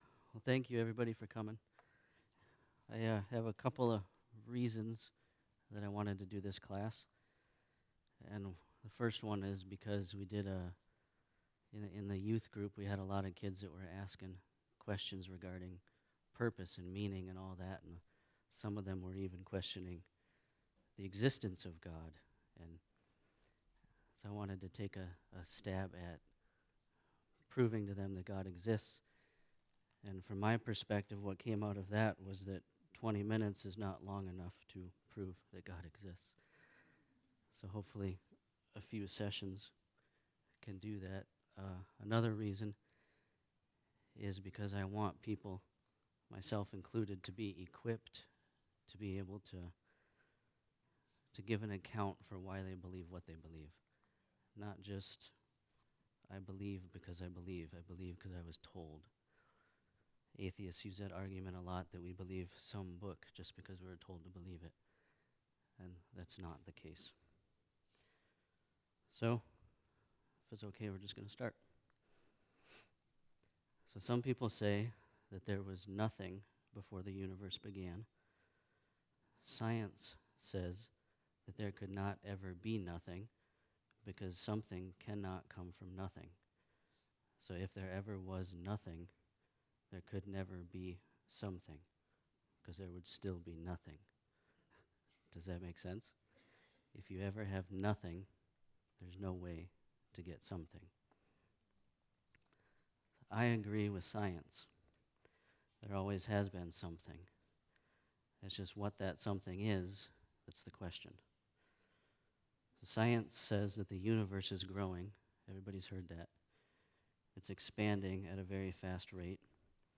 This is a special class-series Friendship church is providing for all who want to not just say they believe in God, but to prove He exists.